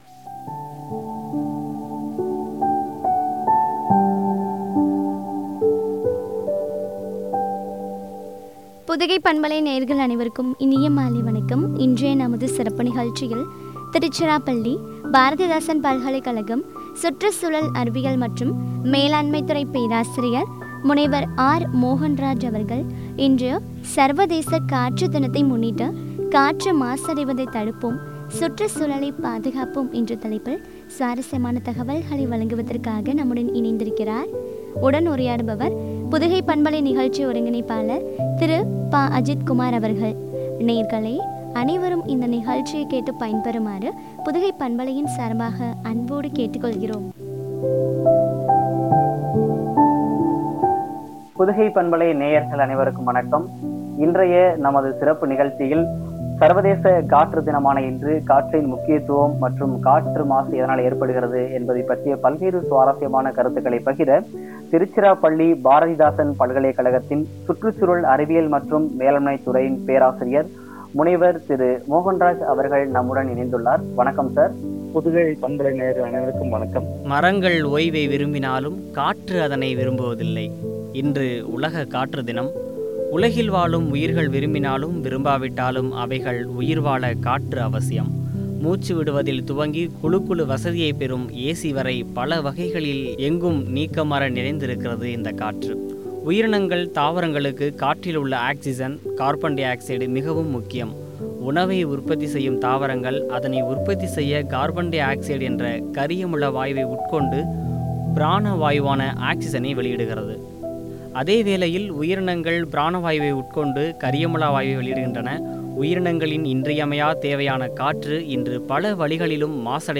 சுற்றுச்சூழலை பாதுகாப்போம் குறித்து வழங்கிய உரையாடல்.